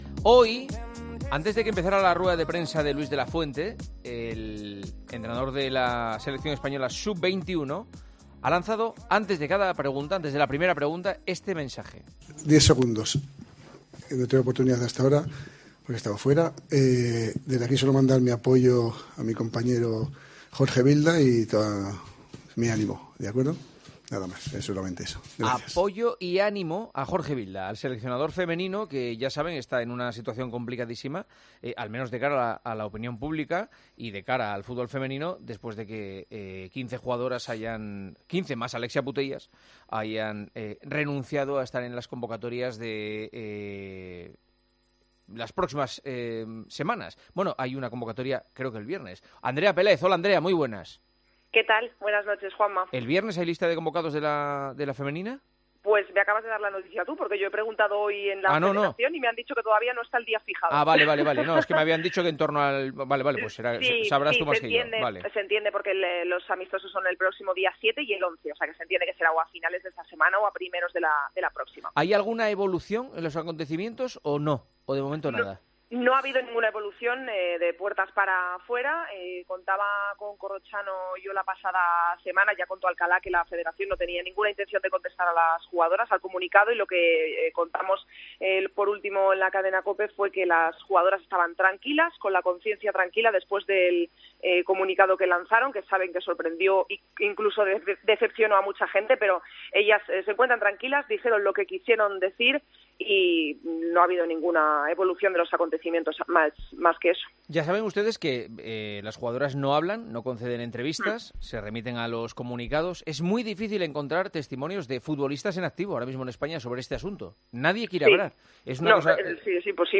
Sin embargo, El Partidazo de COPE pudo charlar este lunes con Natalia Pablos .